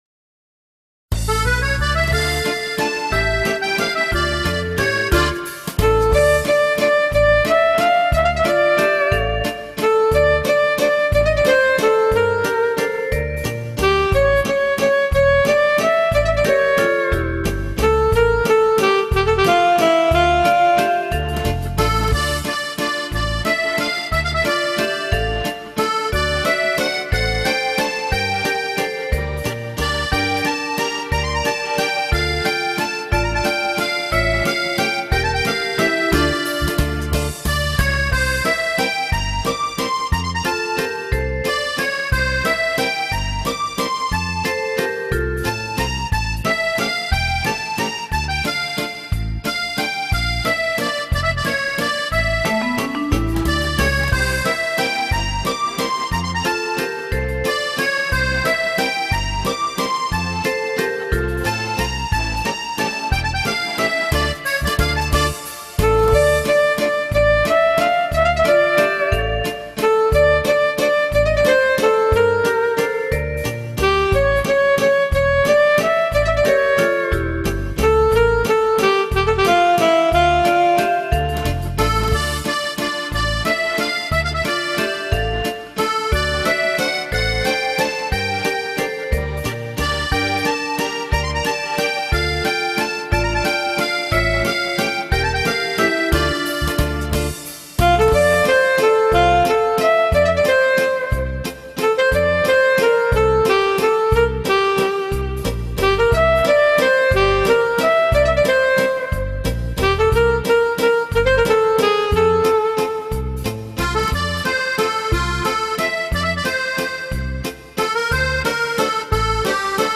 Вальс.